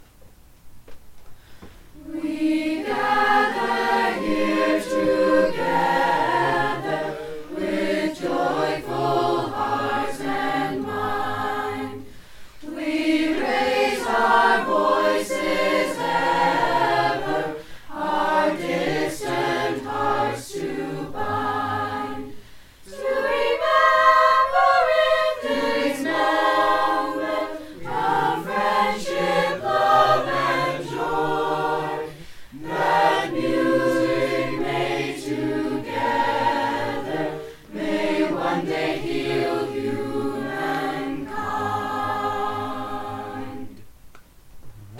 the students
Chamber, Choral & Orchestral Music
Chorus